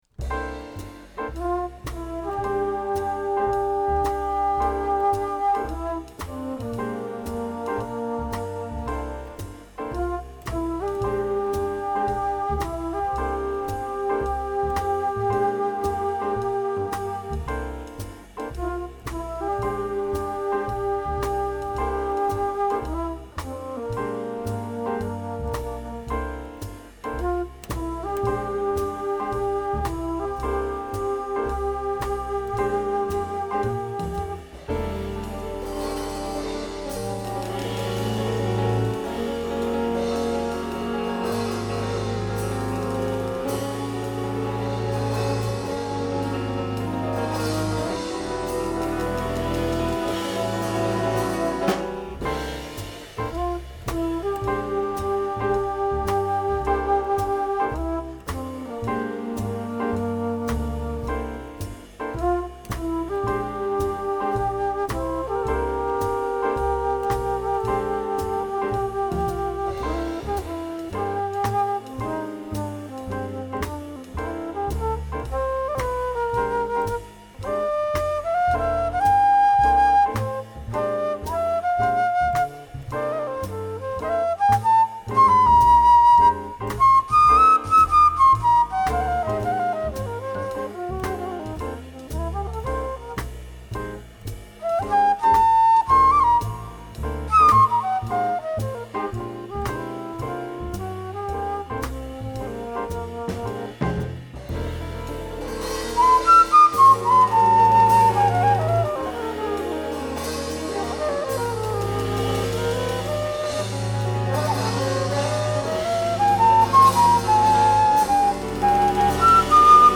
with all melodies and solos played by myself on alto flute.